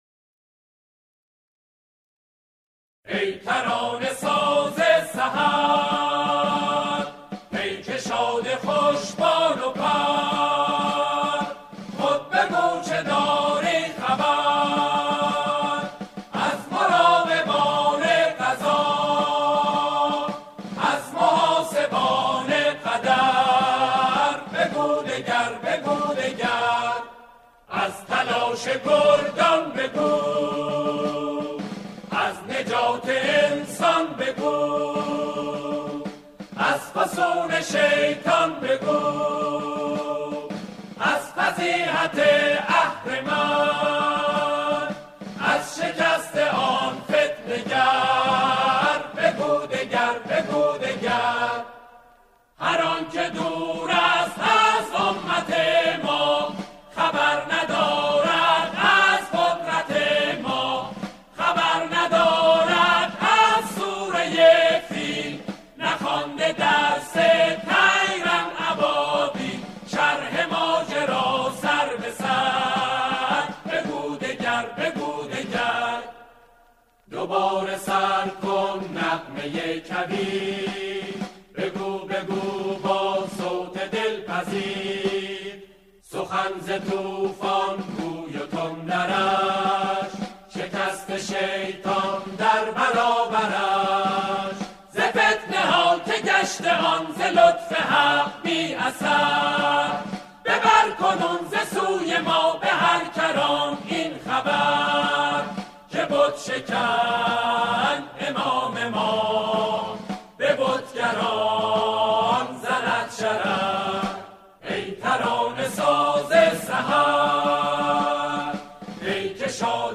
سرودهای دهه فجر